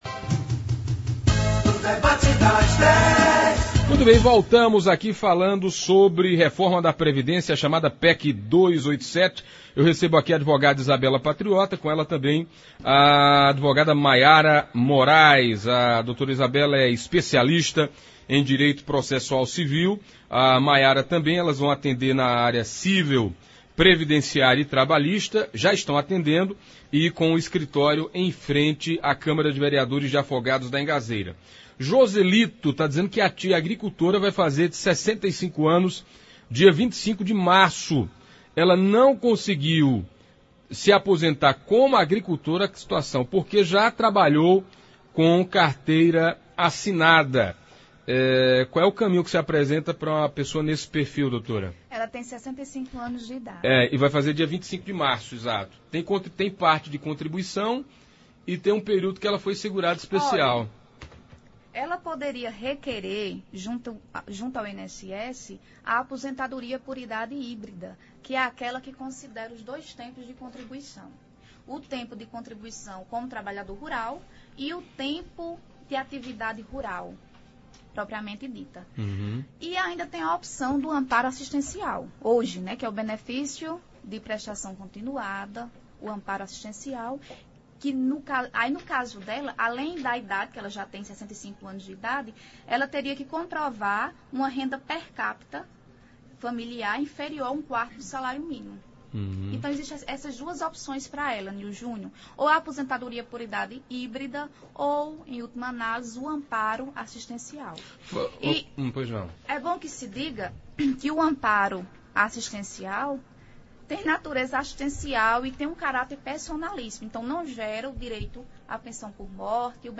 Na Pajeú, advogadas falam sobre a reforma da previdência – Rádio Pajeú
As advogadas esclareceram muitas dúvidas dos ouvintes e internautas da Pajeú, assim como passaram orientações importantes sobre como proceder em alguns casos. Ouça abaixo uma parte do debate de hoje: